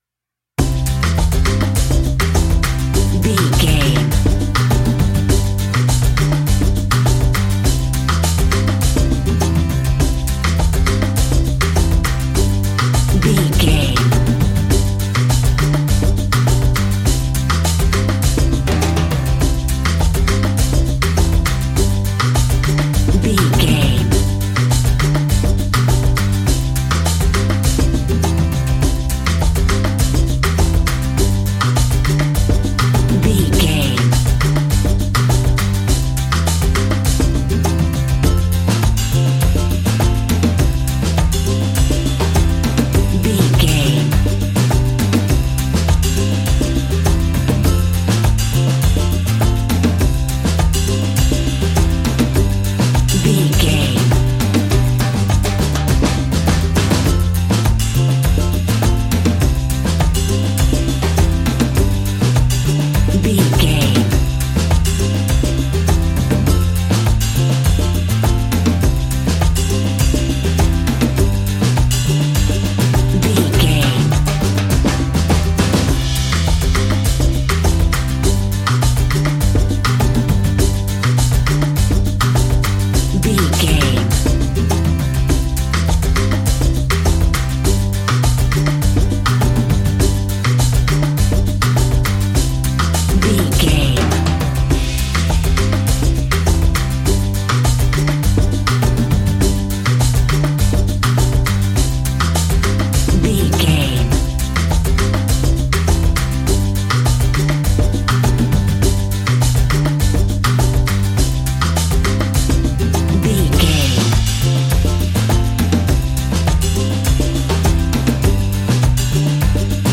Ionian/Major
cheerful/happy
mellow
fun
drums
electric guitar
percussion
horns
electric organ